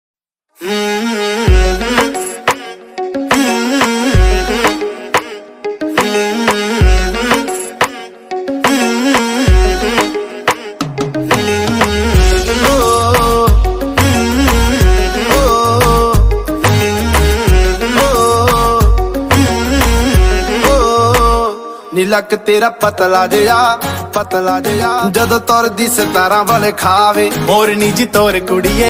Best Punjabi Ringtone